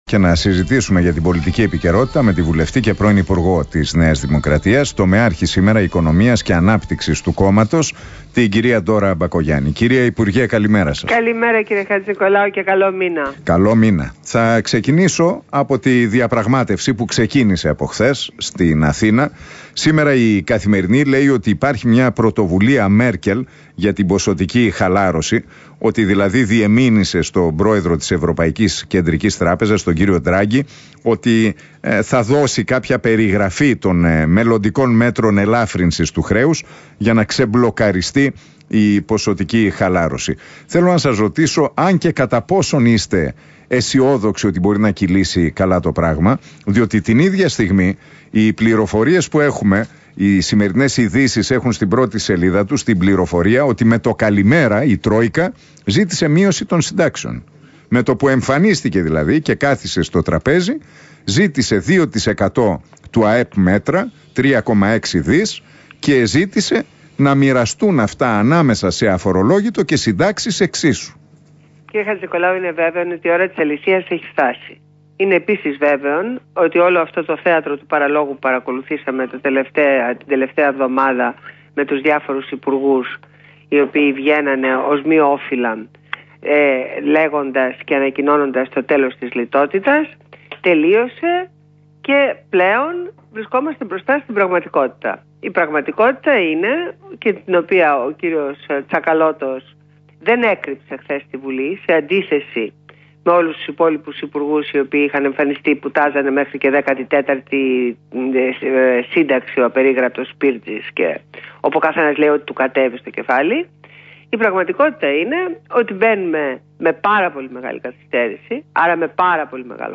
Ακούστε τη συνέντευξη στο ραδιόφωνο του REALfm στο δημοσιογράφο Ν. Χατζηνικολάου